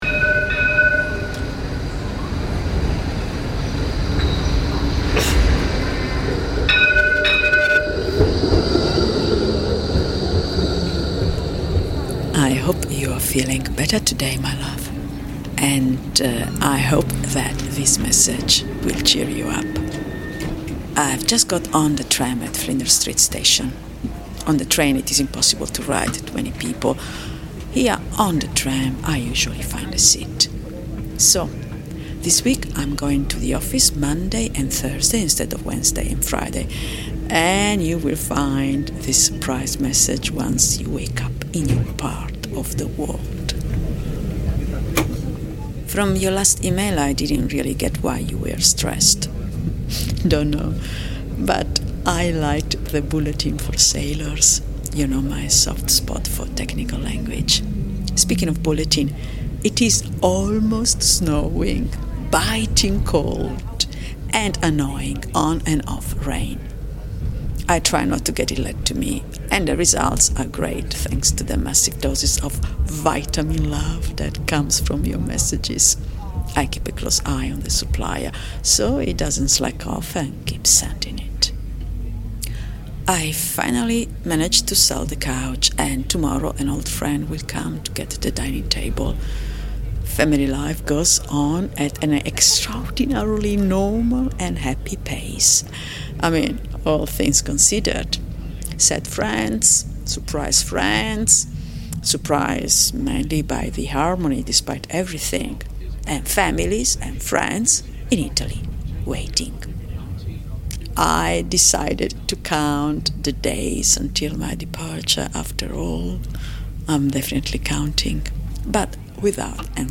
Melbourne tram journey reimagined